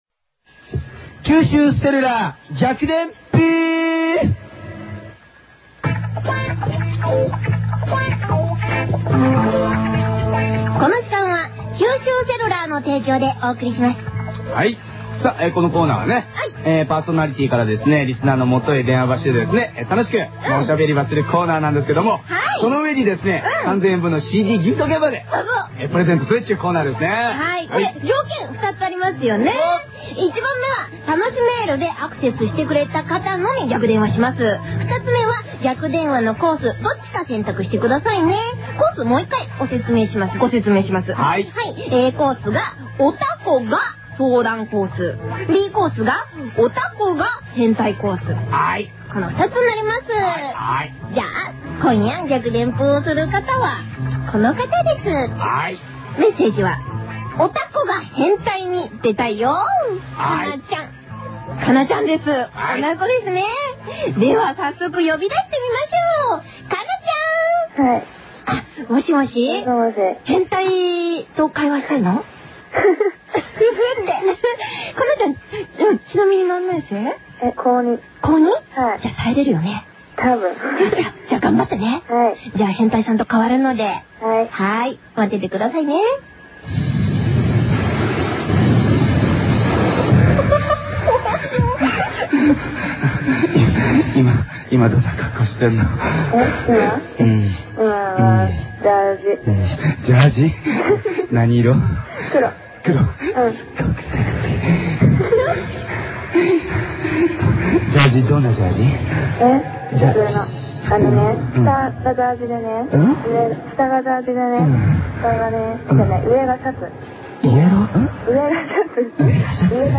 番組スタートから半年間、エンディングの前に行われていた逆電のコーナー。